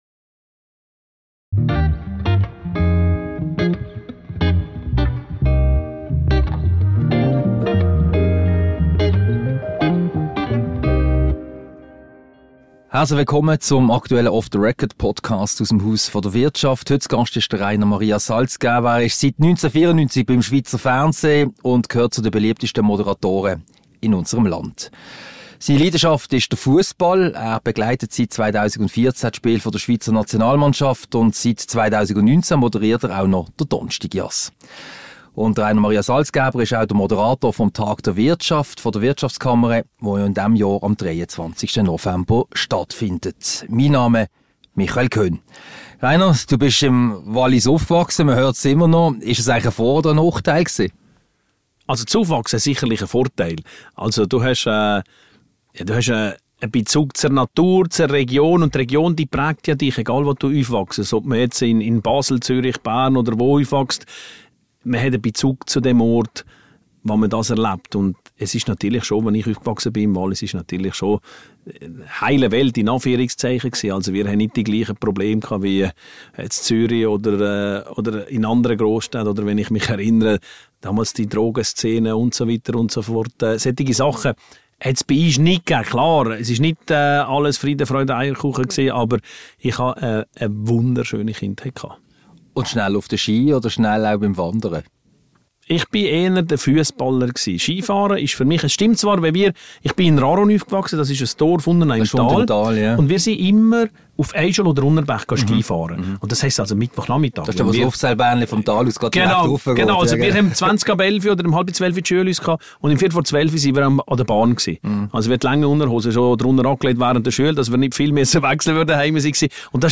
Mit Rainer Maria Salzgeber (Moderator und Kommentator). Ein Gespräch mit Rainer Maria Salzgeber über das Aufwachsen im Wallis, seine ganz persönlichen Fussball-, TV-, Jass- und Lebensgeschichten und den Tag der Wirtschaft, den er auch 2023 moderiert.